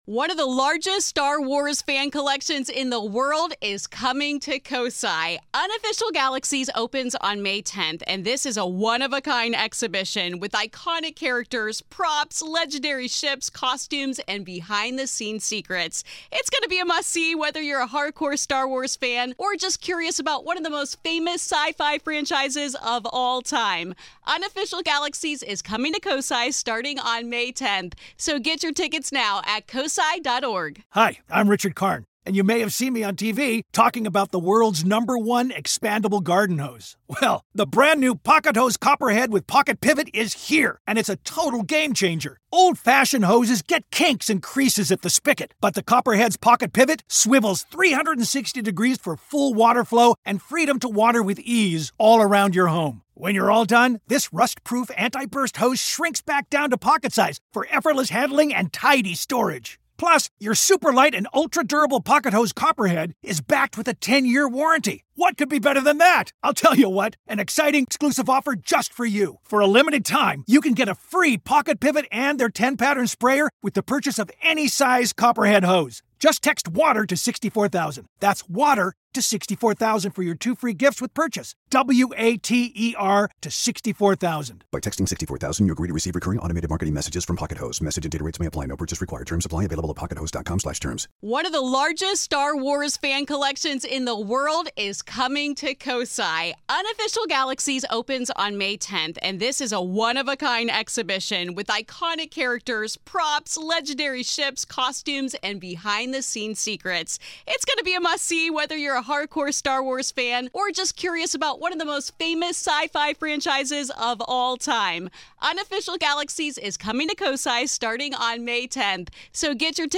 In Part Two of our conversation